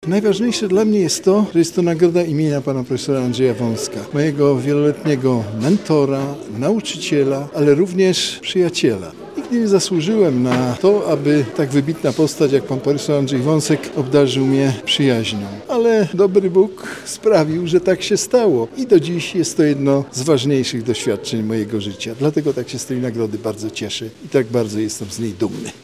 Nagrodę wręczono podczas LubLaw Day na Katolickim Uniwersytecie Lubelskim.